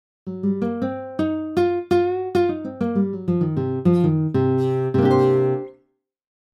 ブルーノートスケールさんを使った音楽は
なんかJAZZっぽい感じだよねっ！
blues.mp3